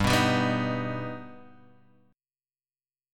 G+ chord